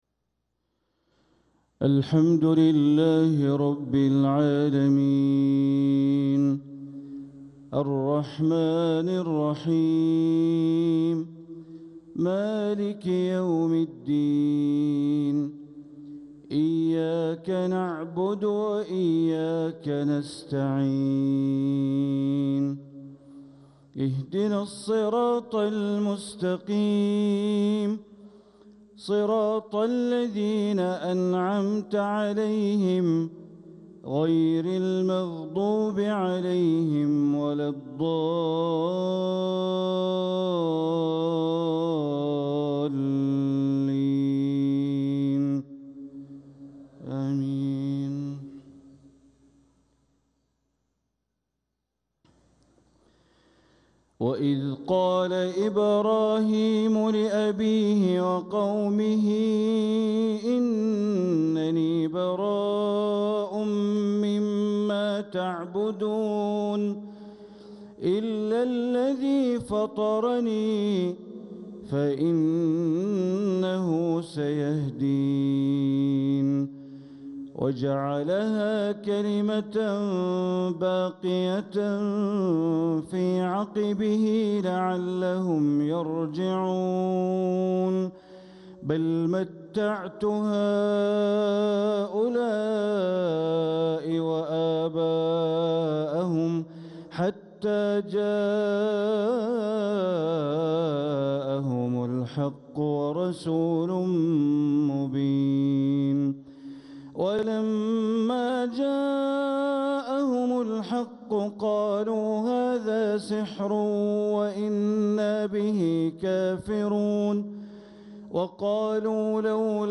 تلاوة من سورتي الزخرف والممتحنة مغرب الأربعاء ٢٨محرم١٤٤٧ > 1447هـ > الفروض - تلاوات بندر بليلة